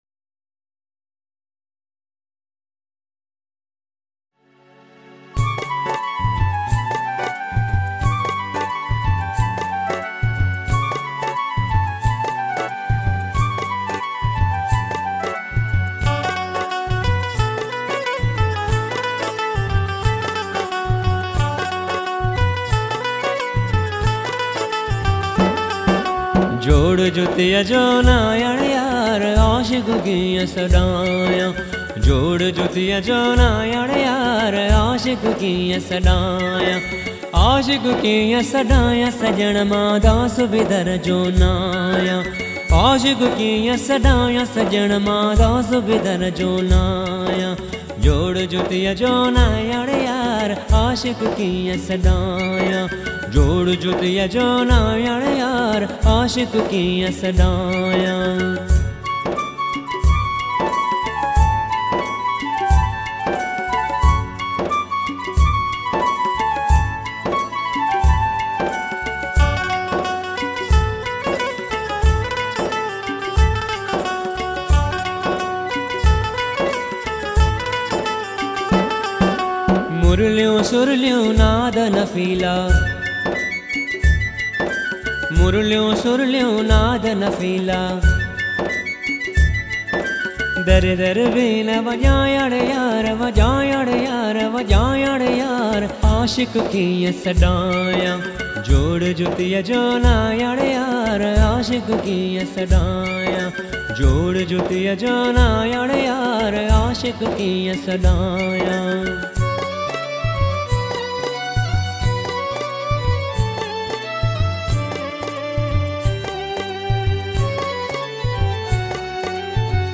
soulful voice